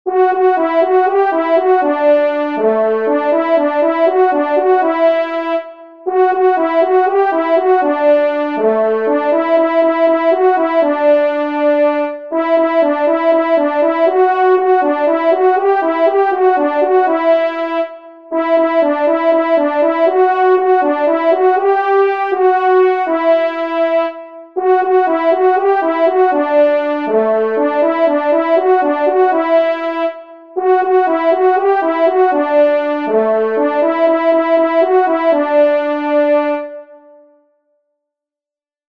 Musique Synthé “French Horns” (Tonalité de Ré